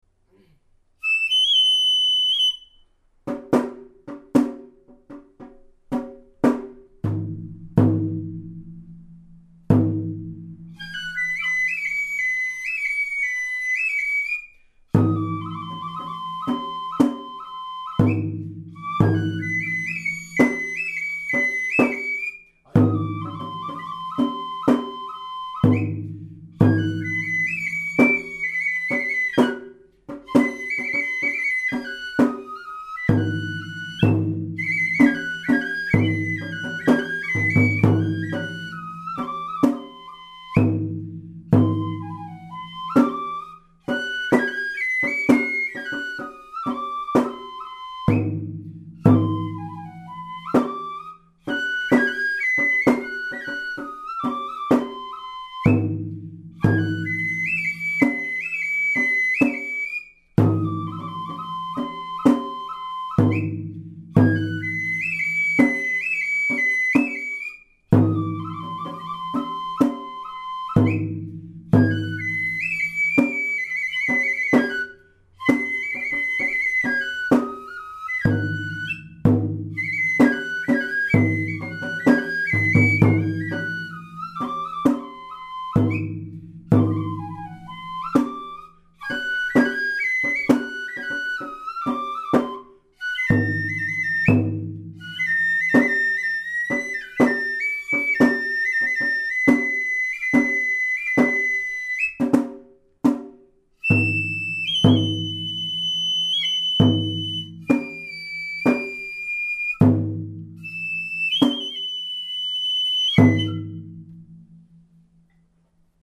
演奏音源：　牡丹
この曲のメロディの特徴は、４行目の部分が明らかなシンコペーションになっているところで、昔の人も結構良いリズム感をしていたのだなと感心します。